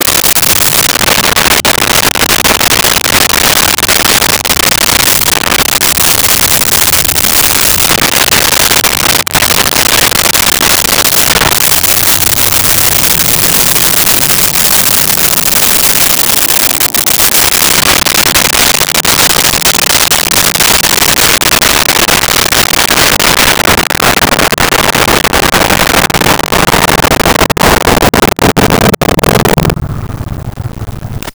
Muscle Car Start Rvrse Fast Out
Muscle Car Start Rvrse Fast Out.wav